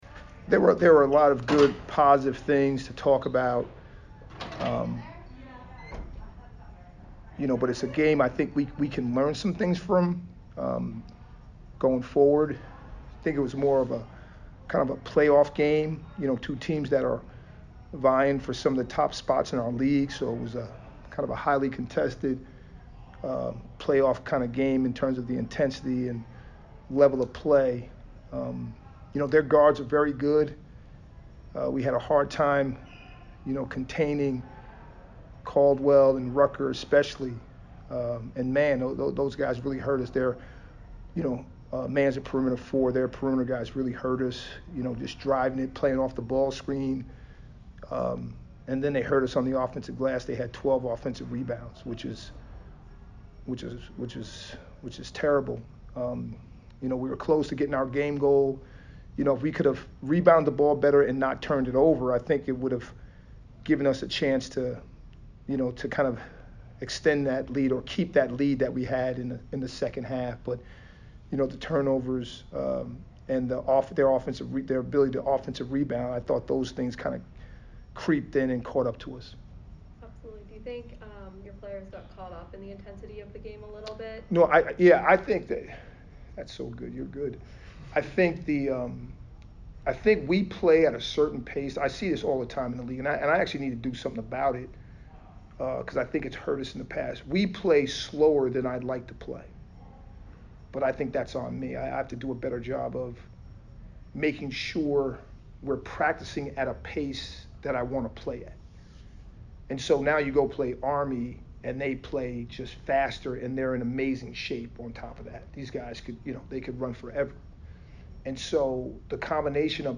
Army MBB Press Conference